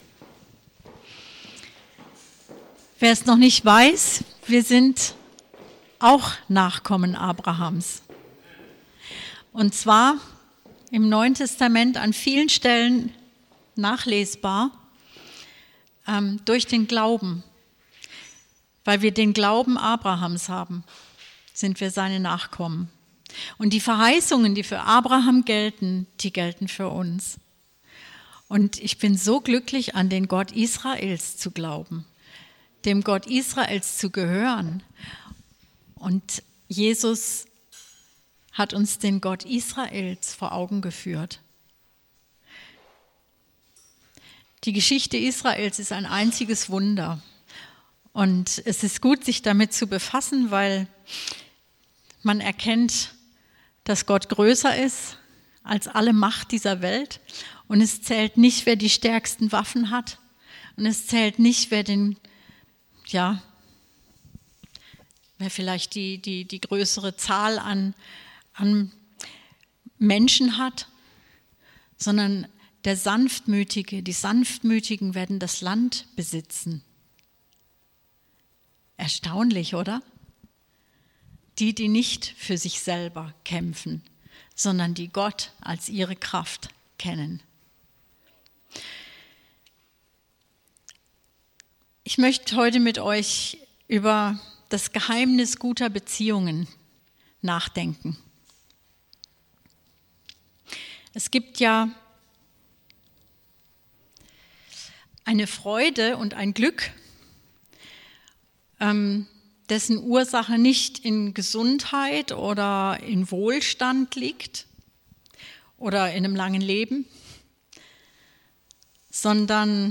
Predigt vom 13.10.2019